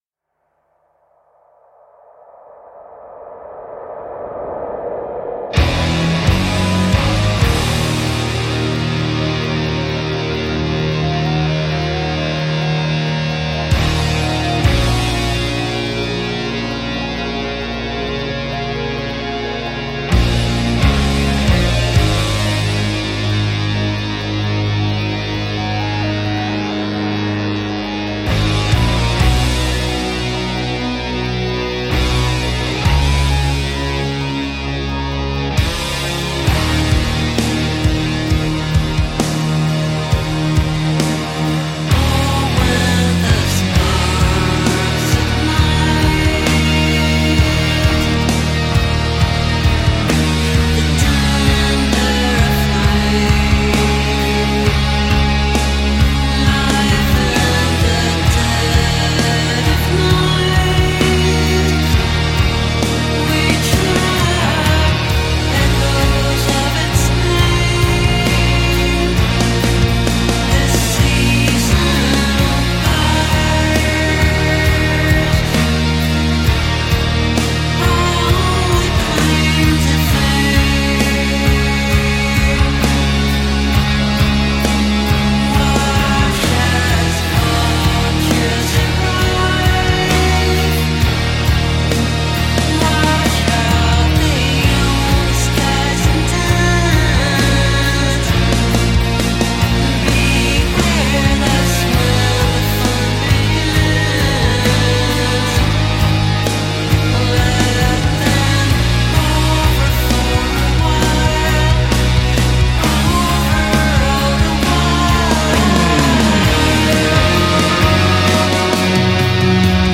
прогрессив краут рок